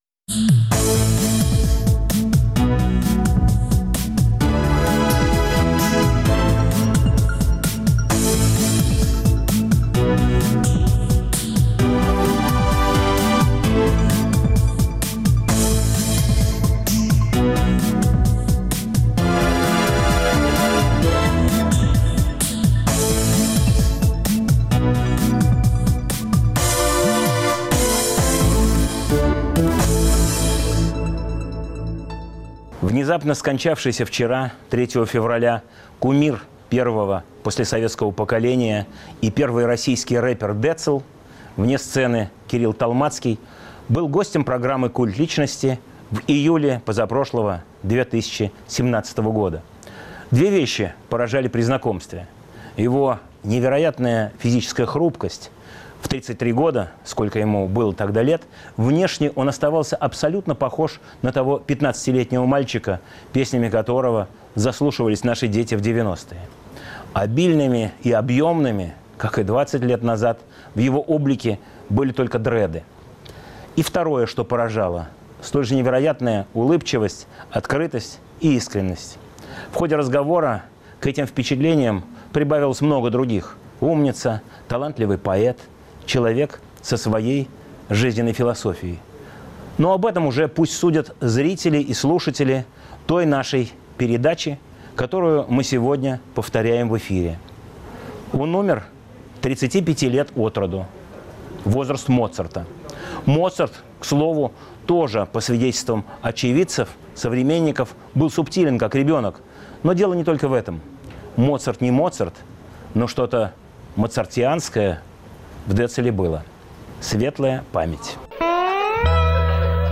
Скоропостижно скончавшийся 3 февраля кумир первого послесоветского поколения и первый российский рэпер Децл (Кирилл Толмацкий) был гостем программы Культ личности на Радио Свобода в июле 2017 года.